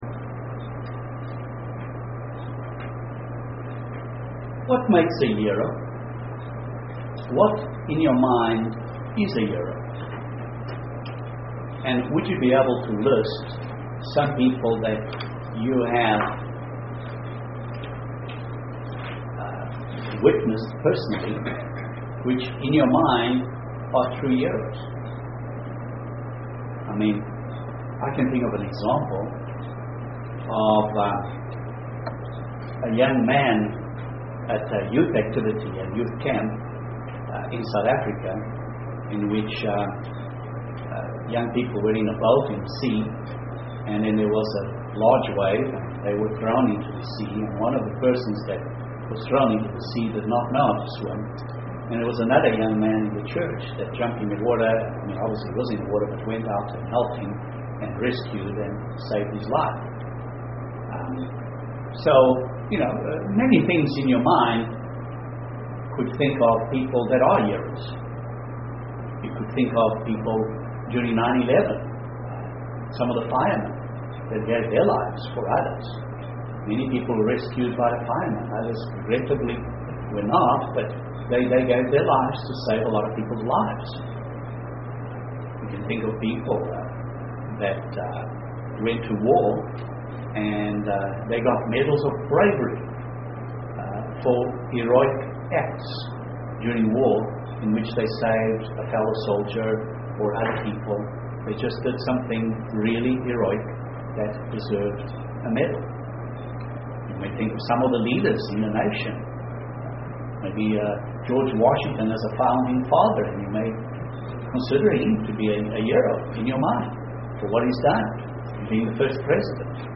What makes one a hero UCG Sermon Transcript This transcript was generated by AI and may contain errors.